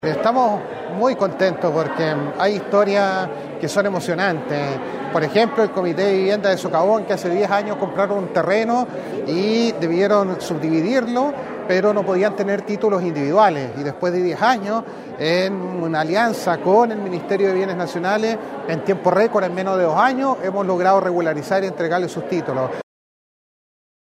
En el salón principal de la casa de la cultura de la comuna de Illapel se llevó a cabo la entrega de 67 títulos de domino a beneficiarios y beneficiarias de la capital provincial, instancia que fue encabezada por el Subsecretario de Bienes Nacionales Sebastián Vergara, quien junto a la Delegada Presidencial provincial de Choapa, Nataly Carvajal, el Seremi de Bienes Nacionales, Marcelo Salazar y el edil comunal, Denis Cortes Aguilera, apreciaron como se cristalizaba el sueño de estas familias illapelinas de ser propietarias del inmueble que habitan.